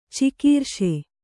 ♪ cikīrṣe